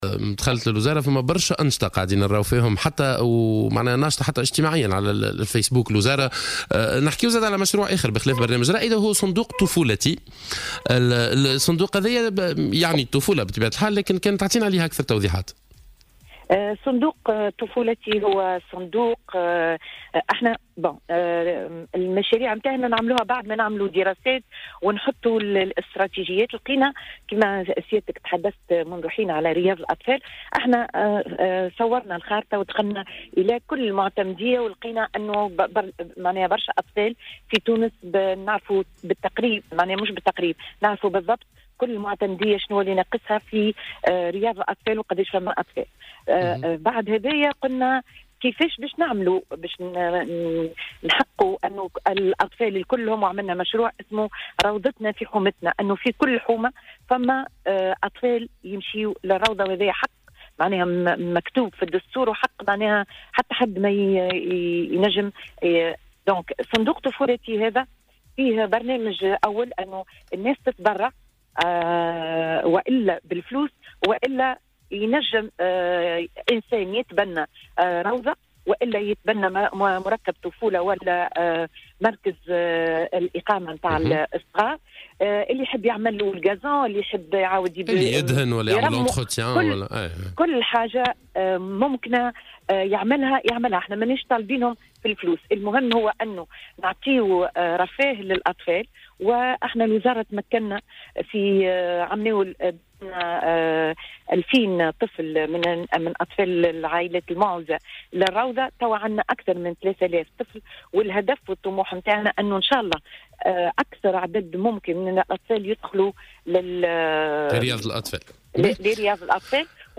وقالت الوزيرة لـ "الجوهرة اف أم" على برنامج "صباح الورد" إنه توجد نحو 4500 روضة اطفال من القطاع الخاص وهناك نية لاسترجاع رياض الأطفال البلدية وإعادة تهيئتها موضحة في هذا الصدد أن الكتاتيب تحت اشراف وزارة الشؤون الدينية بنص القانون ولا يوجد ما يسمى مدارس قرآنية.